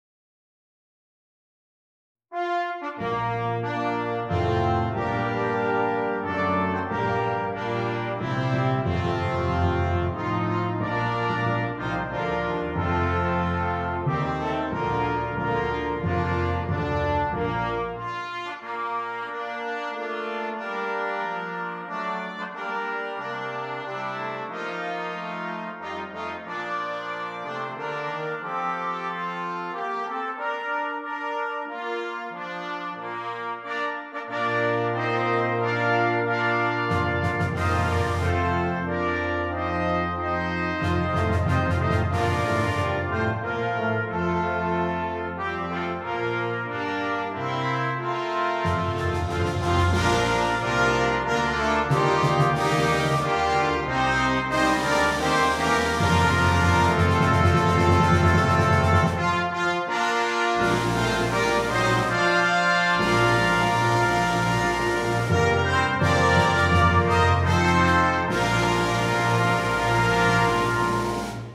Brass Choir (4.2.2.1.1.Percussion)